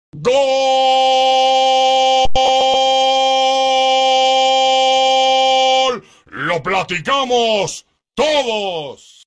Category: Sports Soundboard